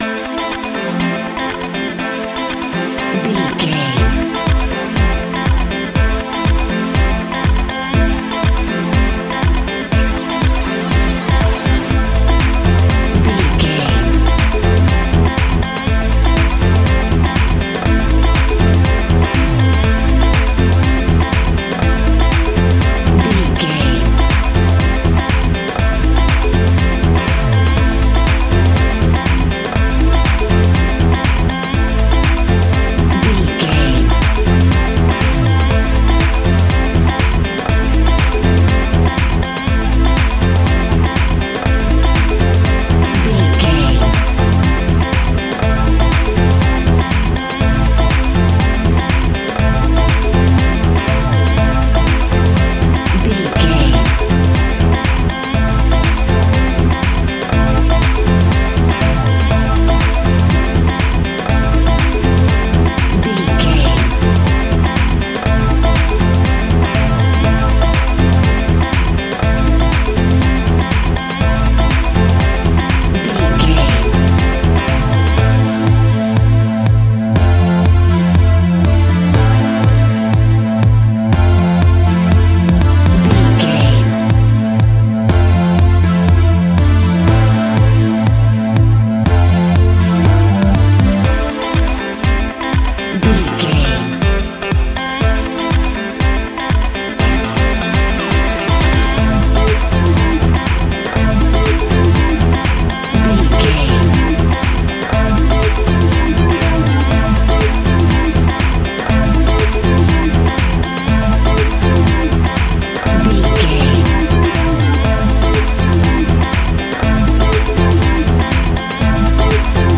Aeolian/Minor
groovy
dreamy
smooth
electric piano
bass guitar
synthesiser
drum machine
disco house
electronic funk
upbeat
Synth Pads
clavinet
horns